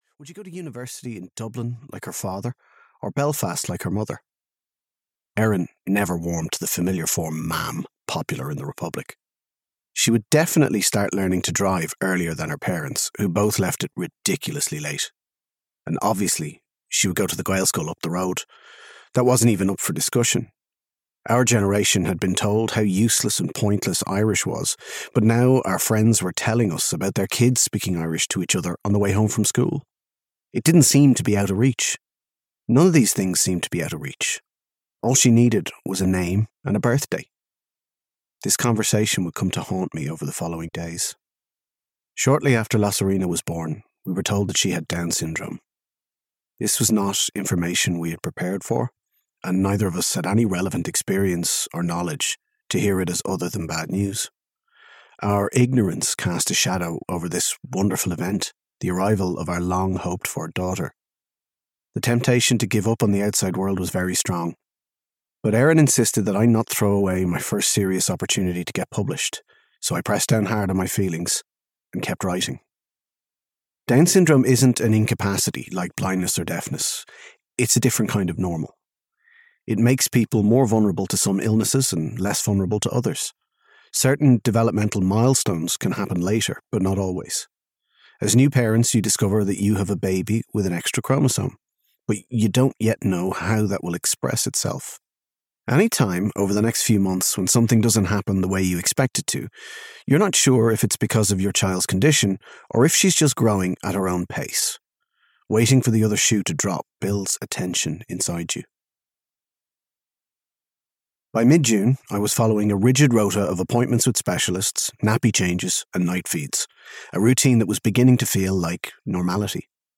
Craic Baby (EN) audiokniha
Ukázka z knihy